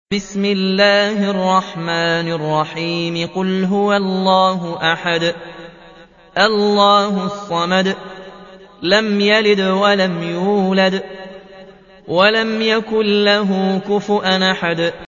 112. سورة الإخلاص / القارئ